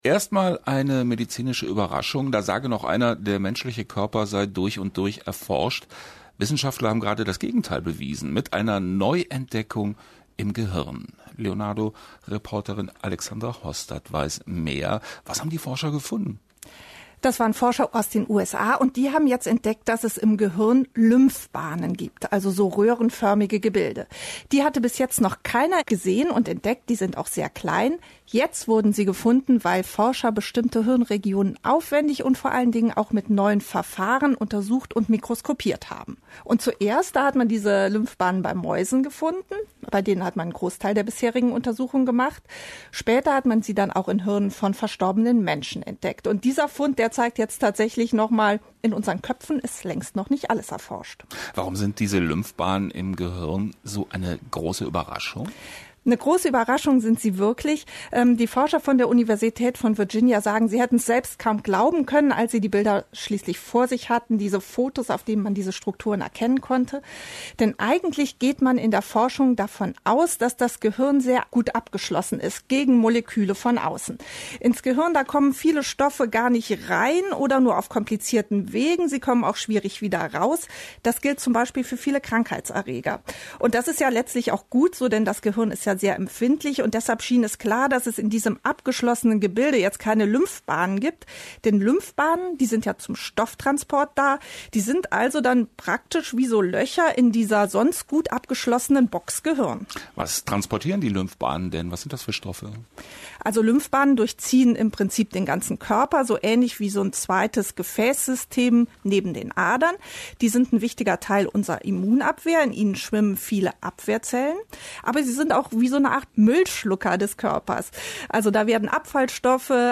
Hörfunk
Neuigkeiten aus der Wissenschaft, live on air.